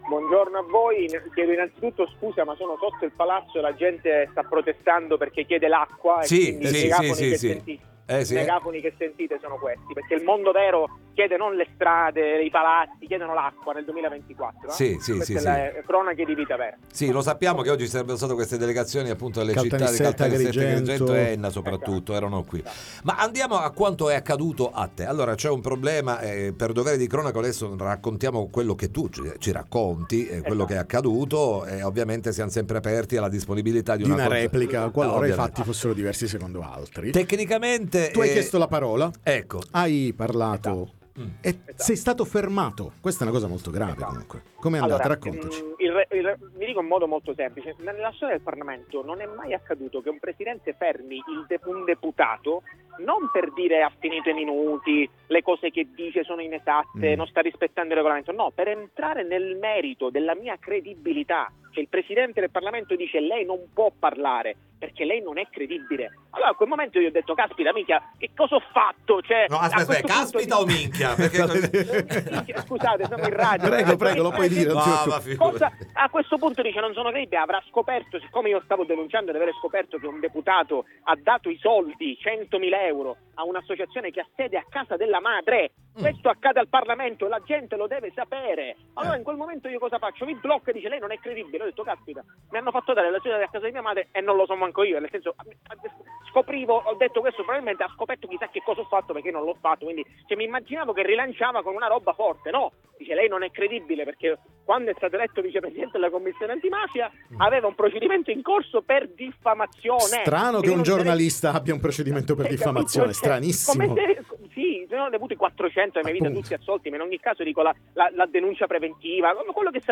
Accuse di populismo e irregolarità e La Vardera viene espulso dall’ARS: “Qui non siamo alle Iene”, ne parliamo con lui ai nostri microfoni